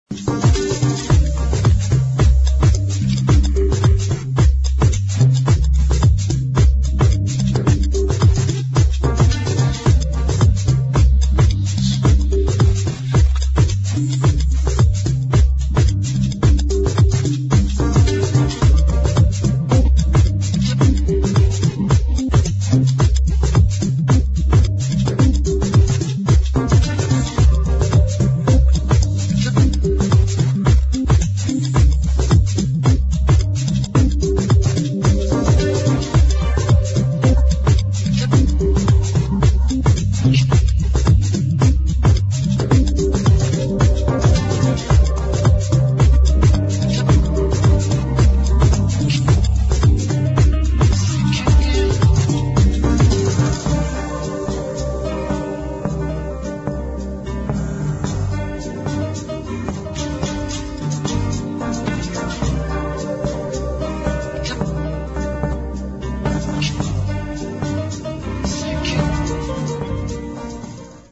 [ DEEP HOUSE | TECH HOUSE ]